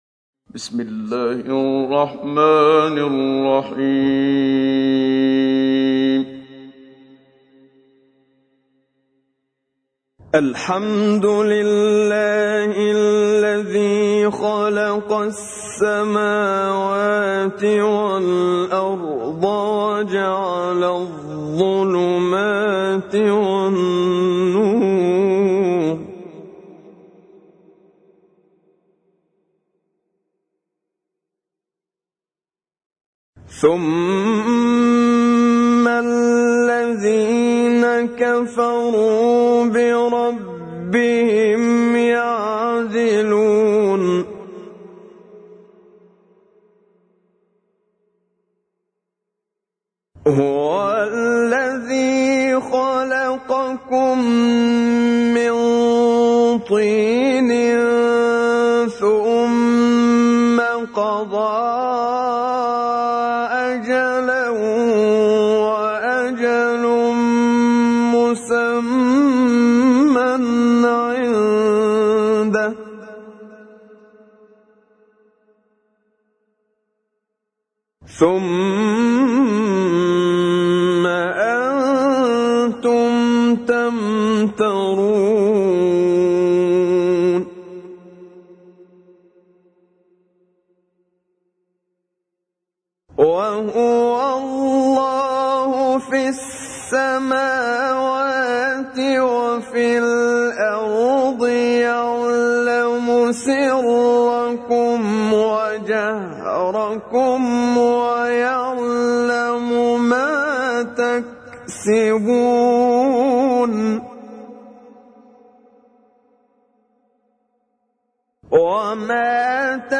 تحميل : 6. سورة الأنعام / القارئ محمد صديق المنشاوي / القرآن الكريم / موقع يا حسين